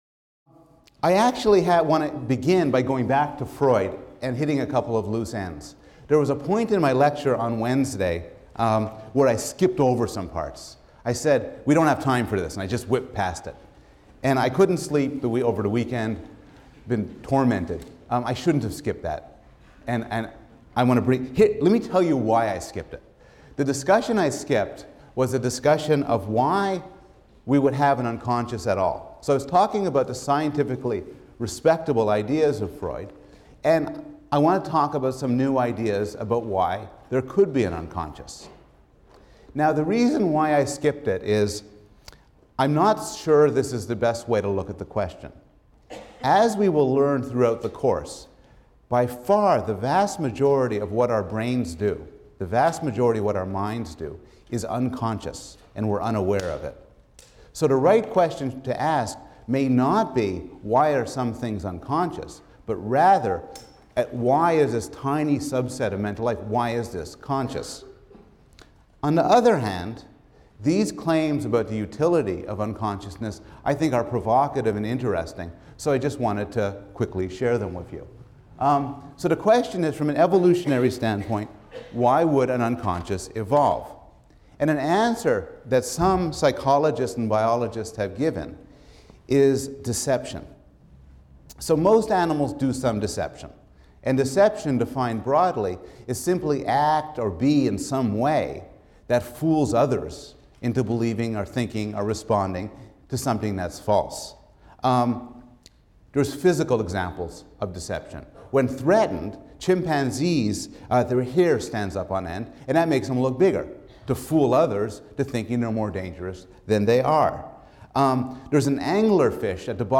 PSYC 110 - Lecture 4 - Foundations: Skinner | Open Yale Courses